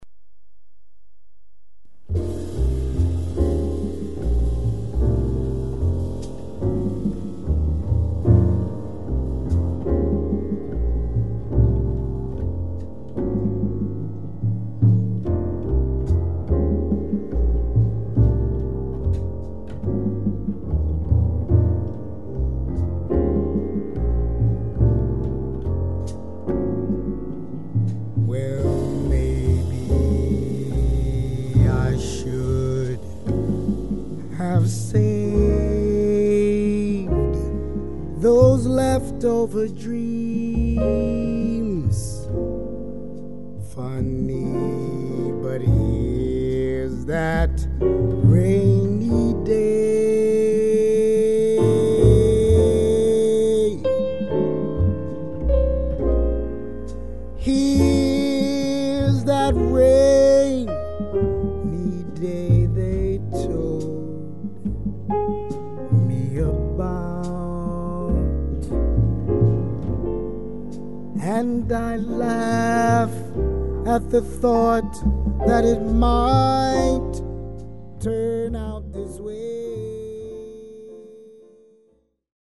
vocals
soprano, tenor sax
piano, Fender Roads, Synthesizer
acoustic, electric bass
drums